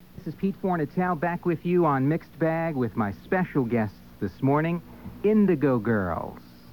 01. introduction (0:06)